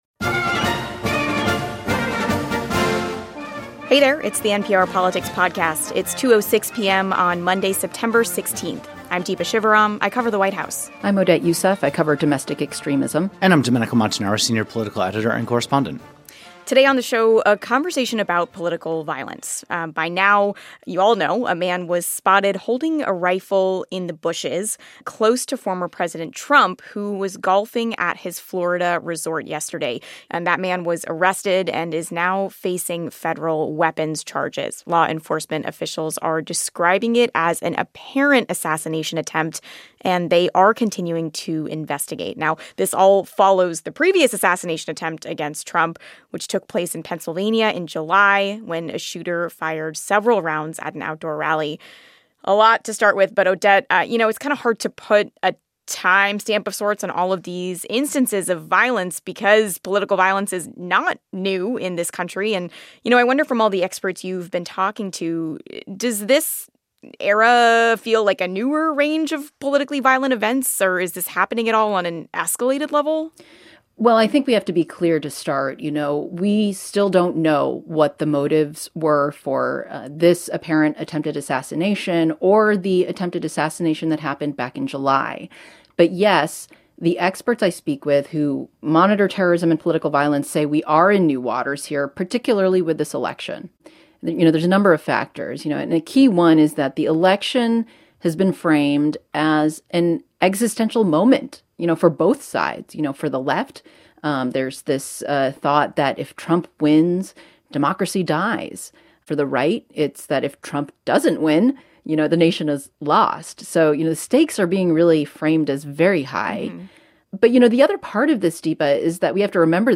A conversation about political violence in the United States